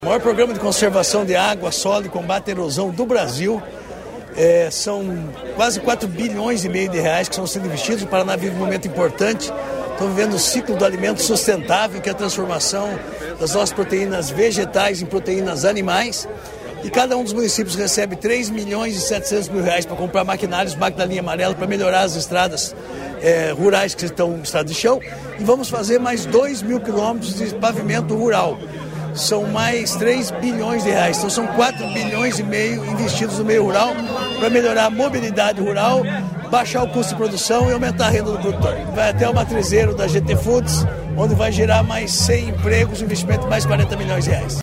Sonora do secretário Estadual da Agricultura e Abastecimento, Marcio Nunes, sobre a pavimentação de estrada que liga sede a distrito de Douradina